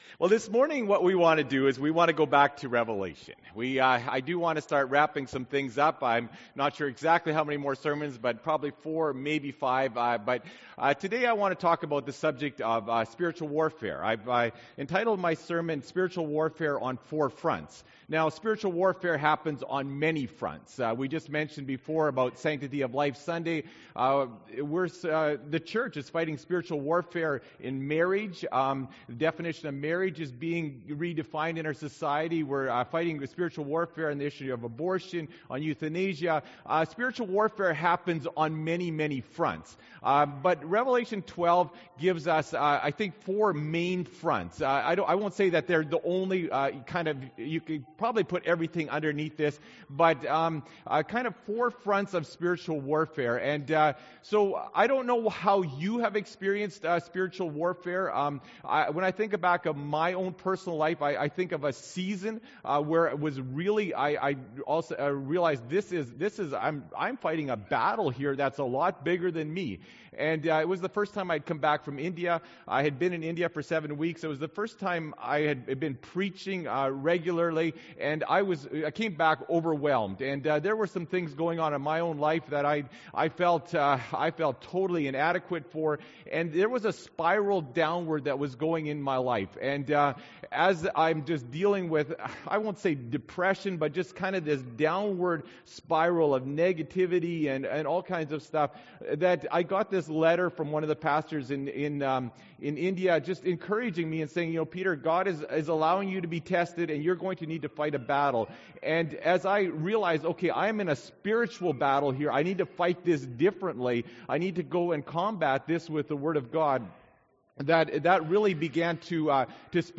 Jan. 24, 2016 – Sermon